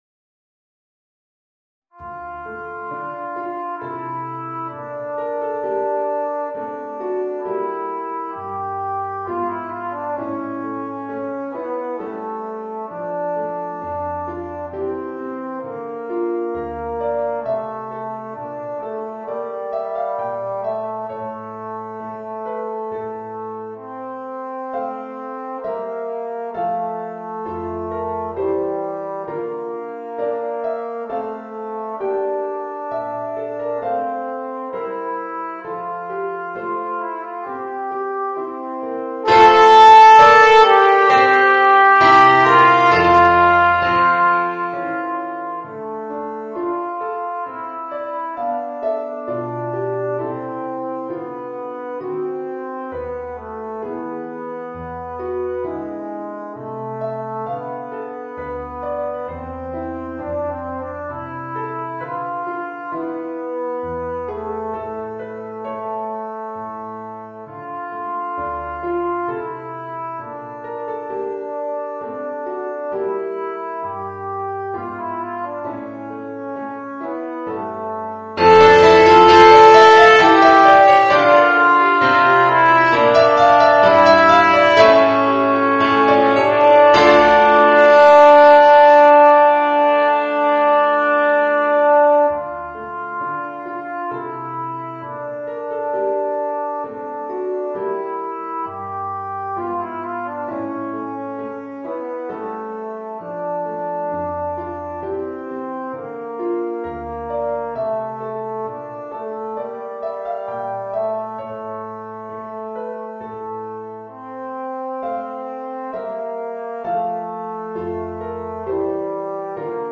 Voicing: Alto Trombone and Organ